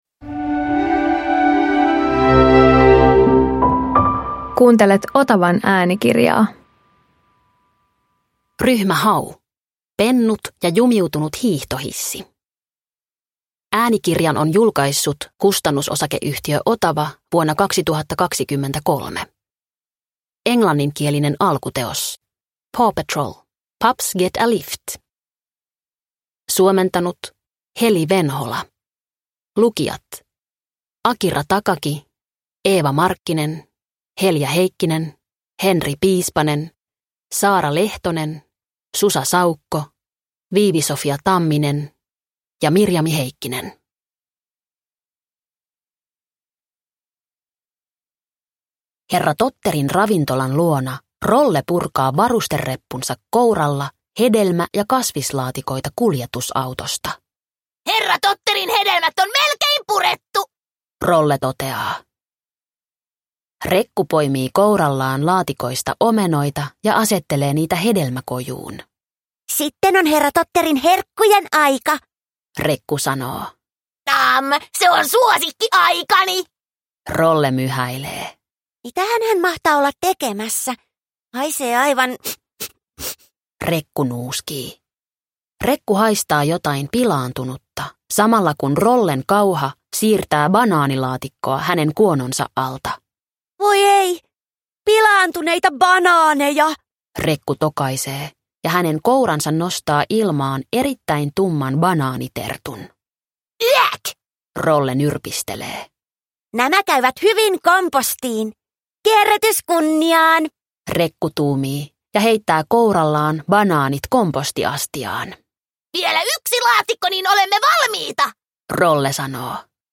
Ryhmä Hau Pennut ja jumiutunut hiihtohissi – Ljudbok – Laddas ner